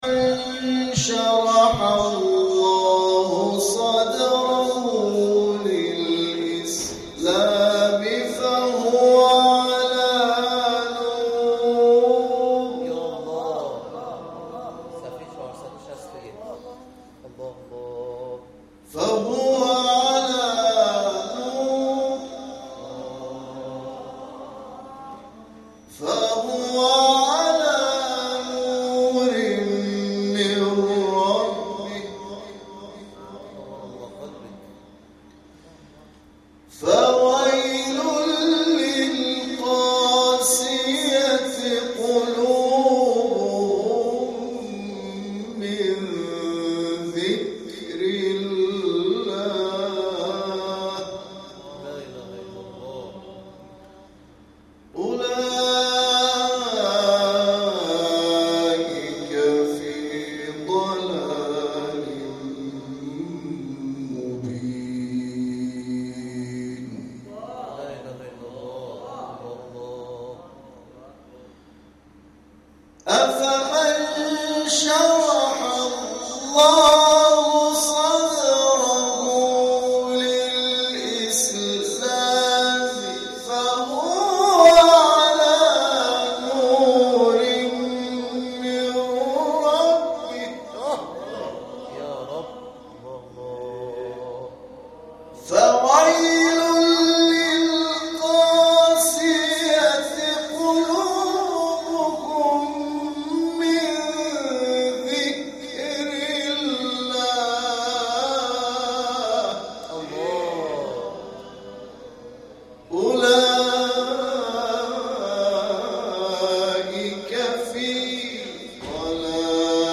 آیاتی از سوره مبارکه زمر، بلد وکوثر را تلاوت کرد